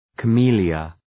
Προφορά
{kə’mi:ljə}